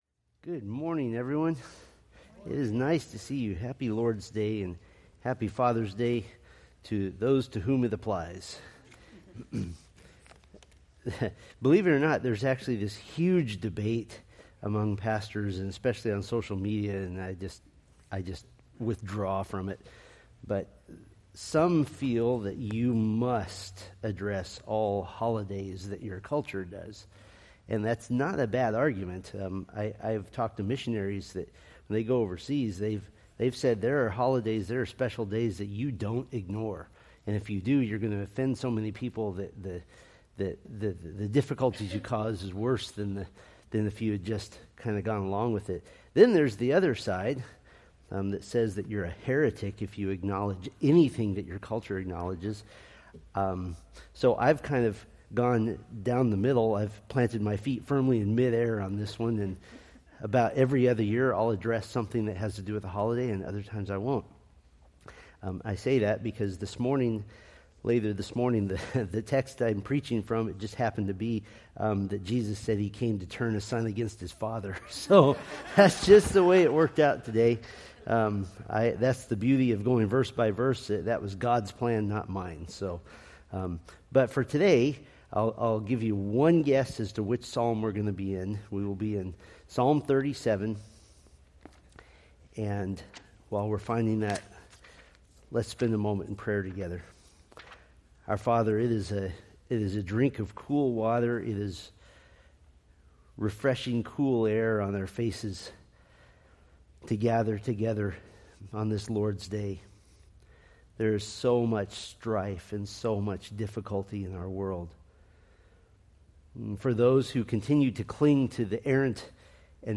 Date: Jun 15, 2025 Series: Psalms Grouping: Sunday School (Adult) More: Download MP3